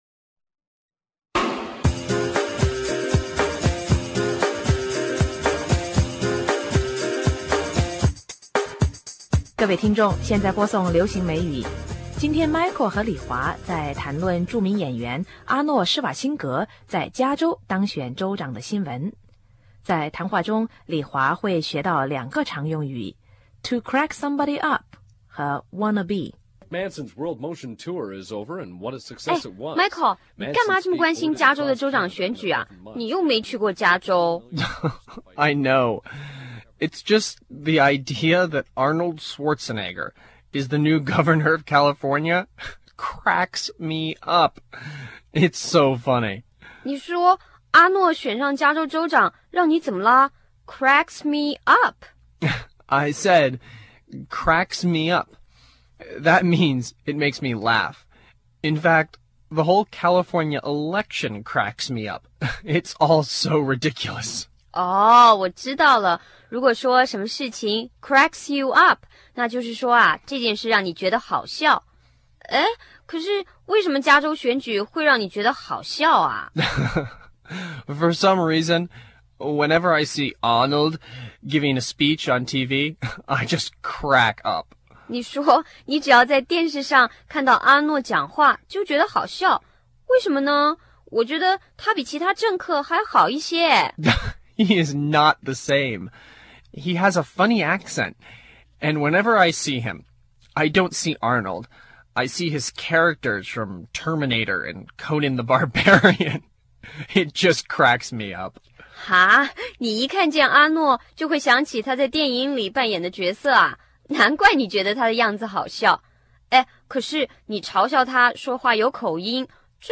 (sound of TV news in the background)